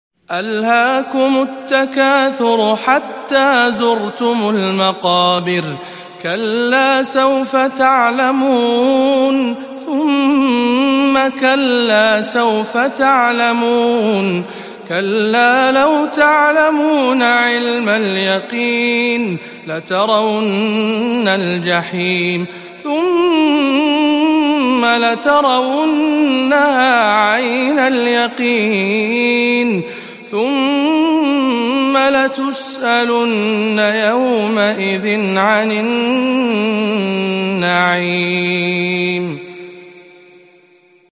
سورة التكاثر - تلاوات رمضان 1437 هـ